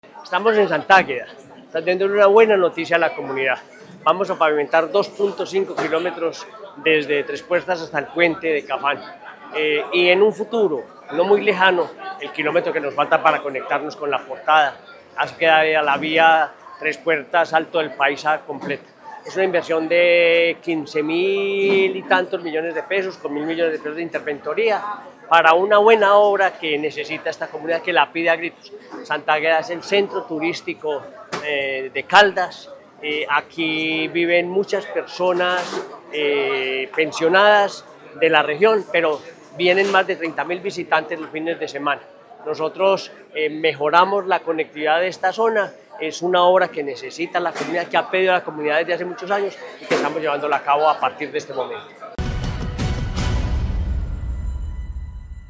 Henry Gutiérrez Ángel, gobernador de Caldas